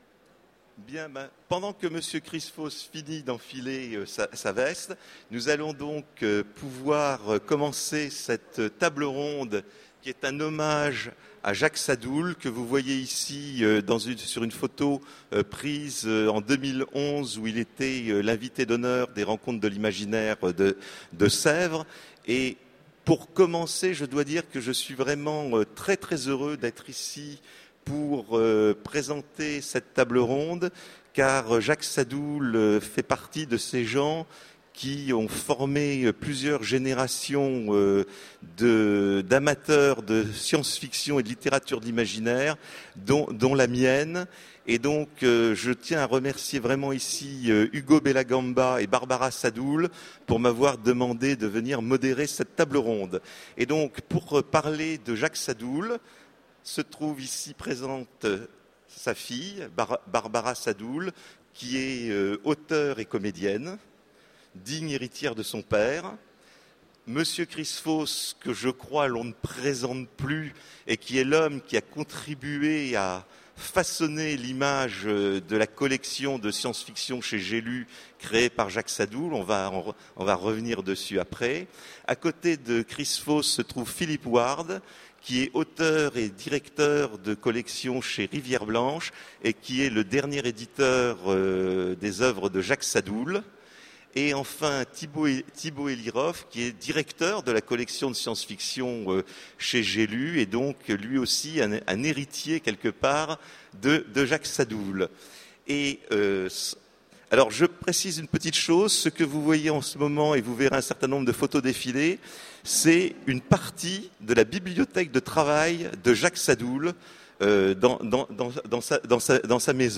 Mots-clés Hommage Conférence Partager cet article